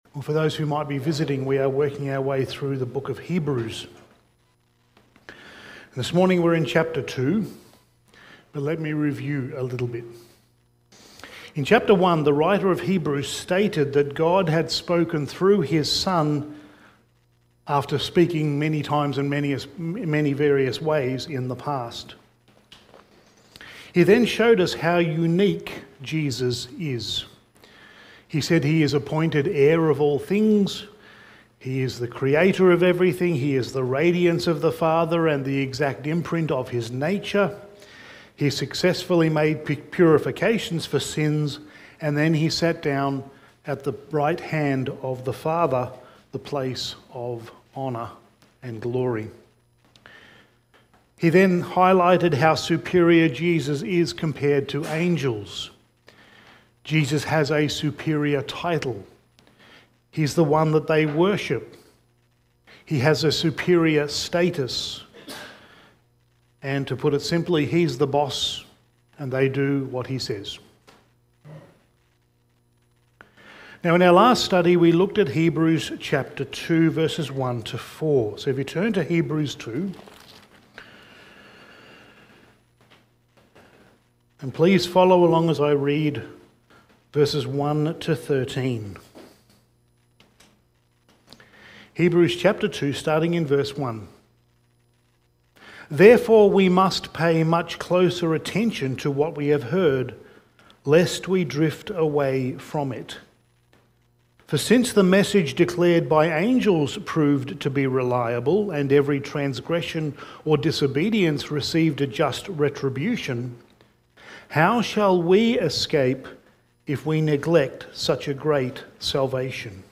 Sermon
Hebrews Series Passage: Hebrews 2:5-9 Service Type: Sunday Morning Sermon 5 « By Works of the Law or by Faith?